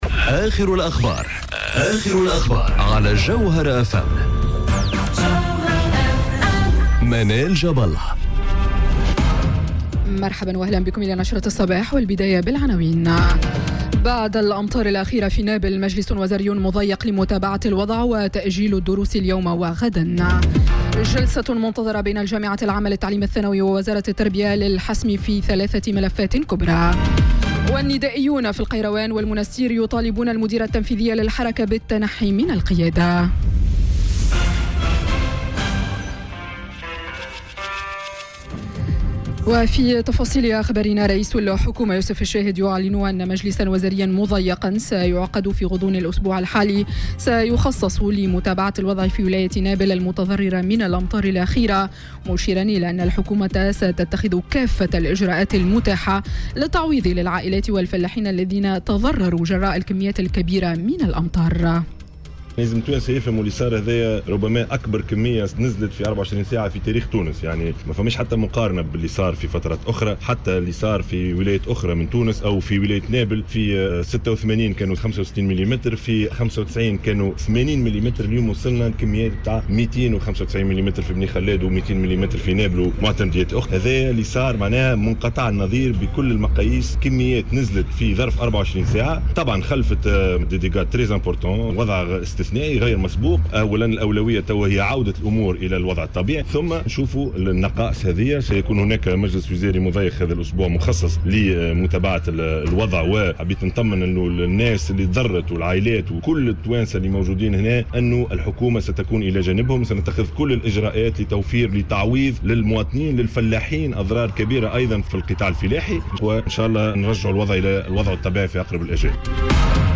نشرة أخبار السابعة صباحا ليوم الإثنين 24 سبتمبر 2018